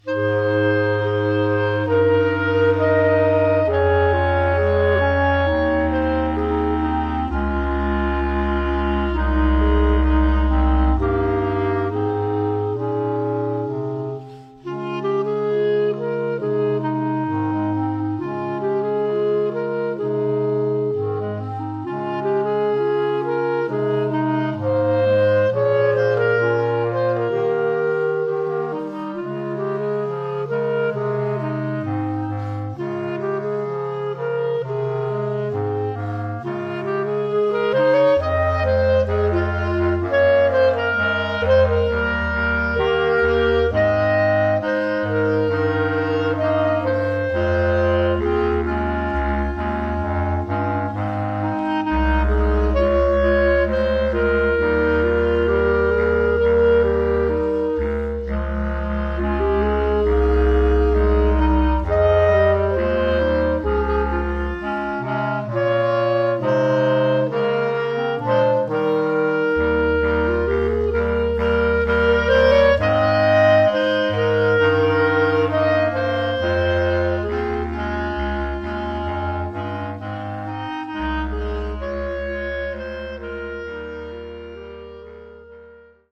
Gattung: für Klarinettenquartett (leicht - mittelschwer)